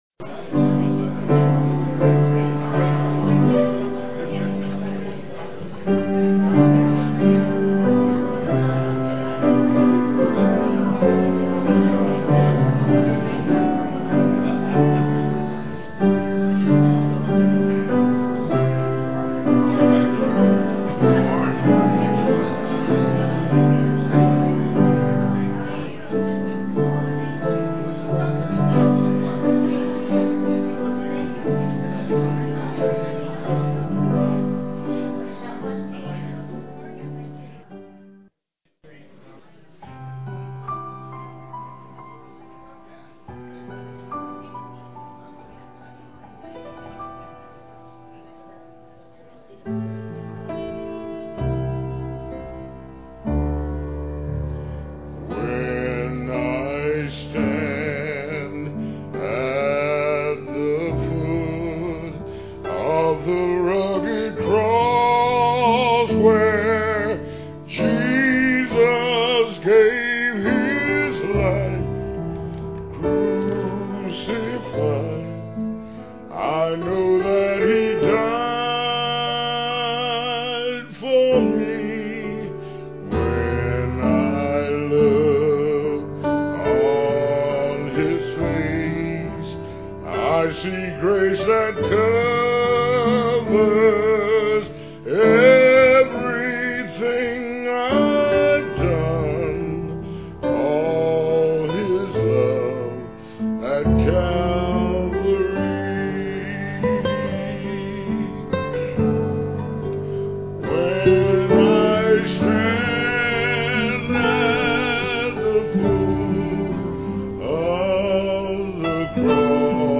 PLAY What a Donkey Can Teach Us, Apr 1, 2007 Scripture: Mark 11:1-9. Scripture reading
Piano and Organ duet
Solo